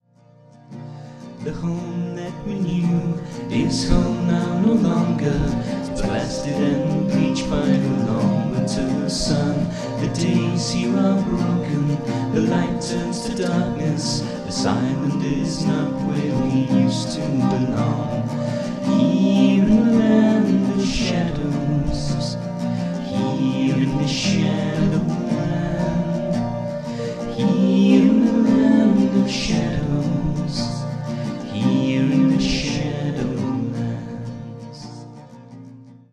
Ibanez PF60CENT1202 'semi' acoustic.
Bodrhan.
Quickshot mic (yes, really!)